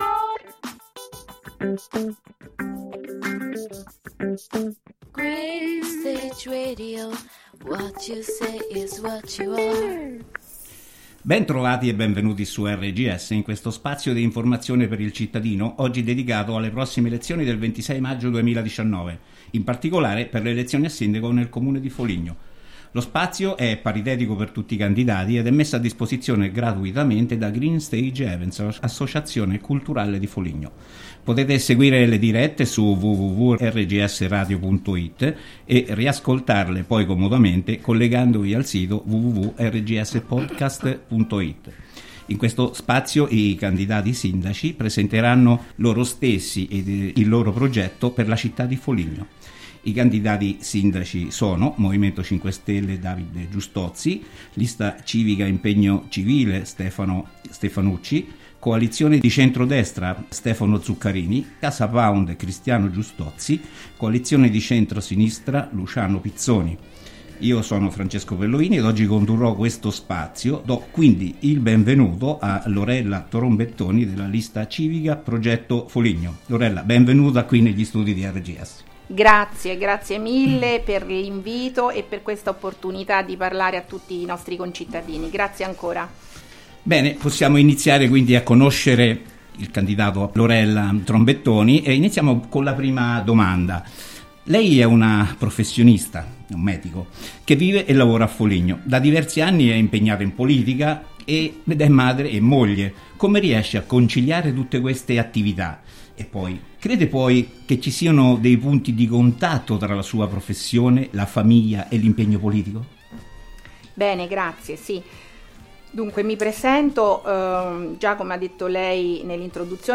Intervista # elezioniamministrative2019 # elezionicomunali # foligno # sindaco # failatuascelta # perilfuturodifoligno